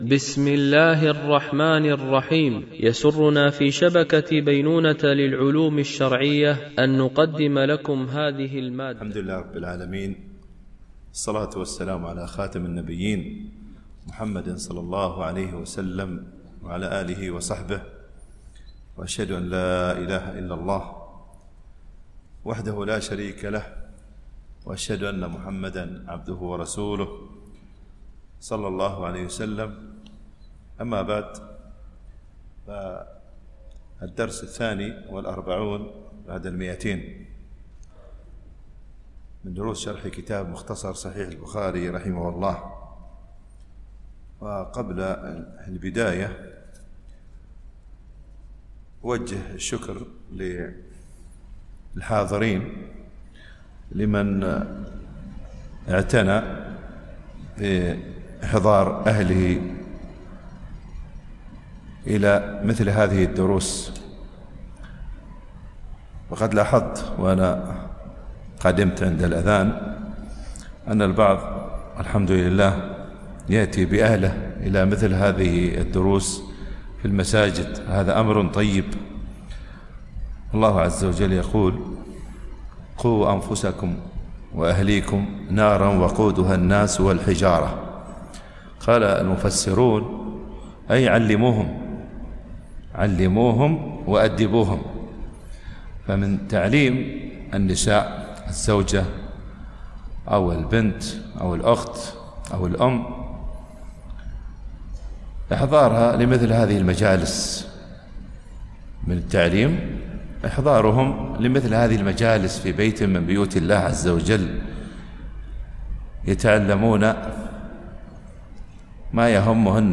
التنسيق: MP3 Mono 44kHz 96Kbps (VBR)